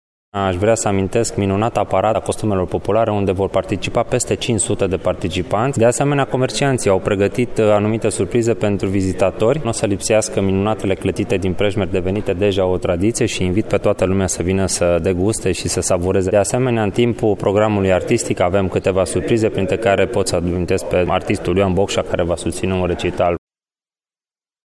Bogdan Loredan, viceprimar Prejmer.